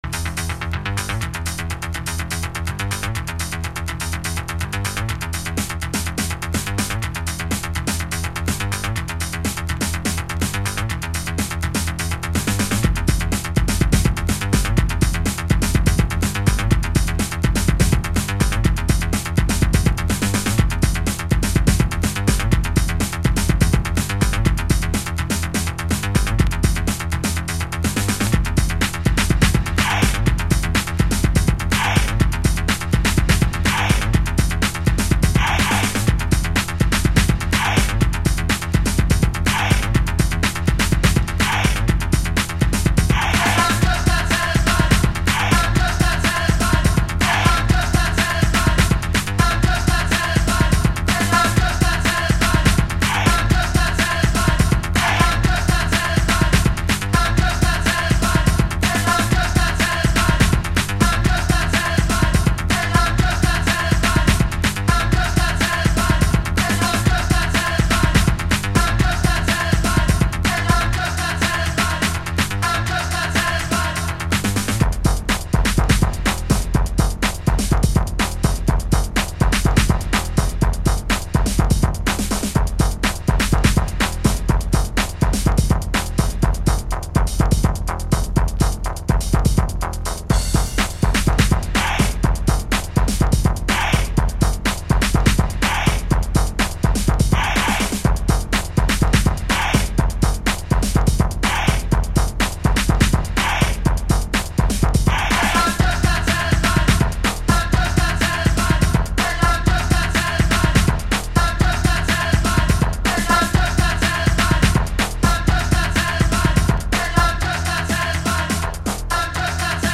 Early House / 90's Techno
FORMAT - 12inch